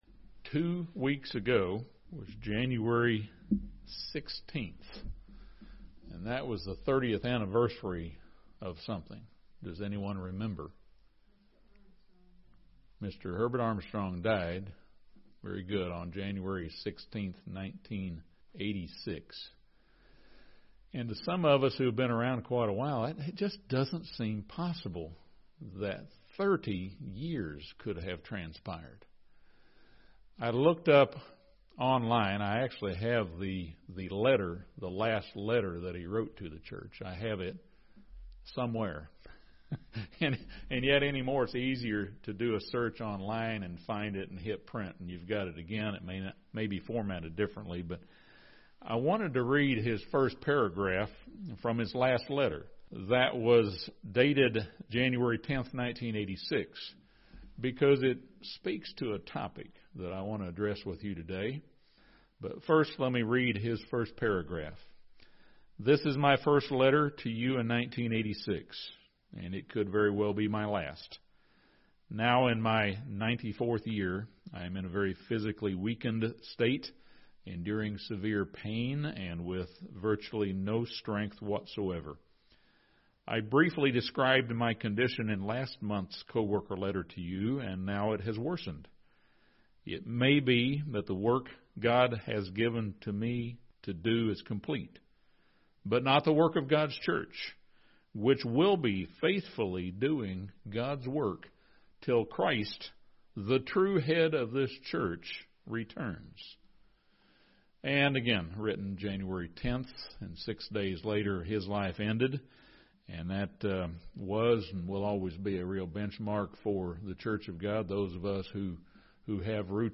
This sermon discuss the landmines we must avoid: dissension, disobedience and disrespect.